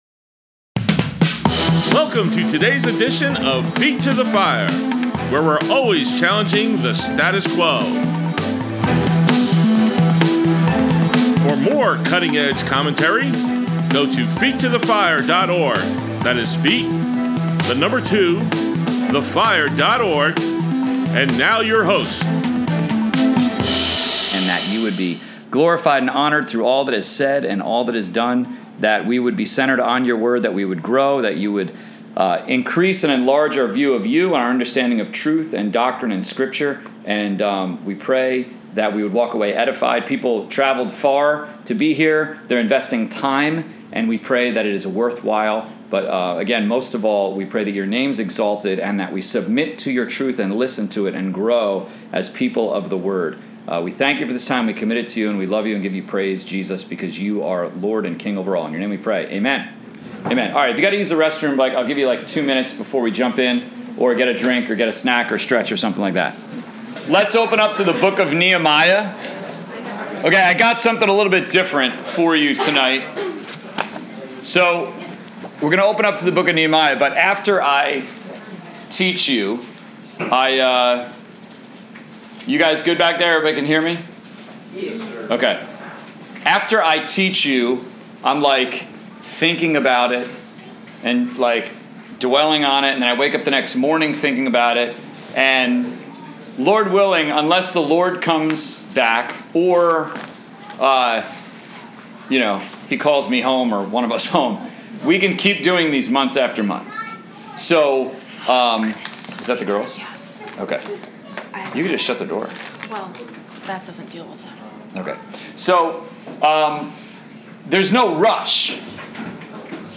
College/Career Bible Study on 7.9.21